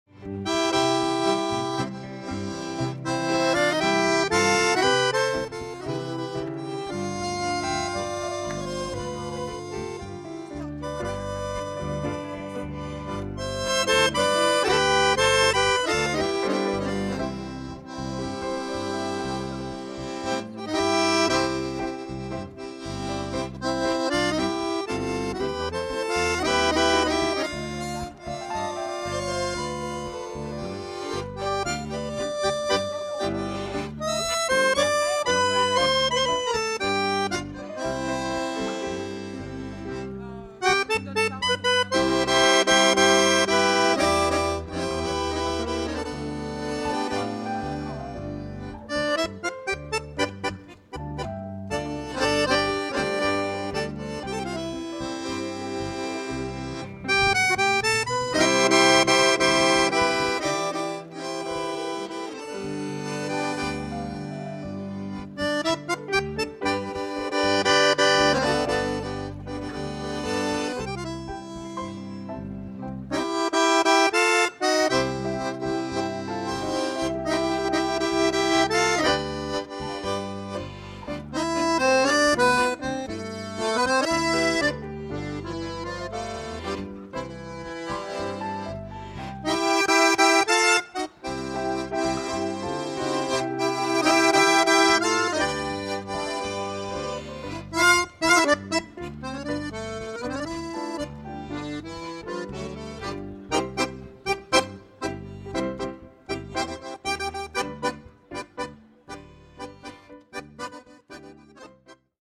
tango